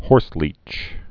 (hôrslēch)